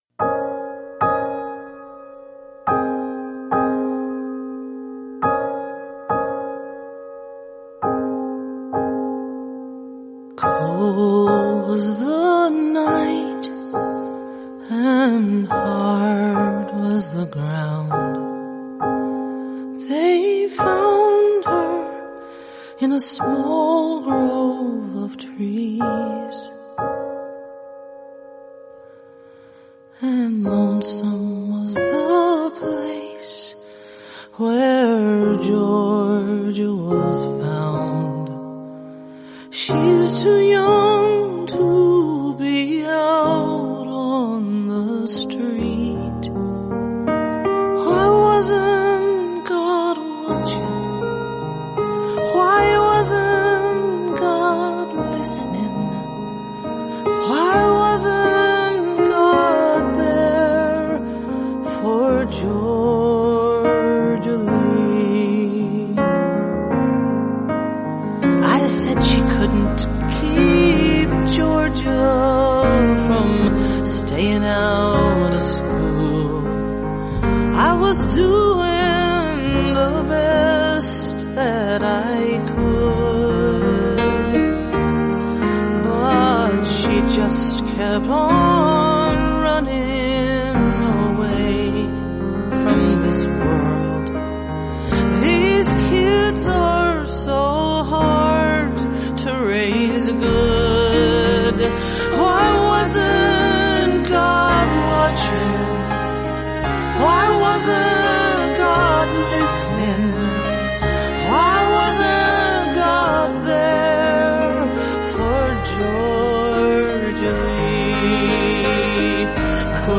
plaintive song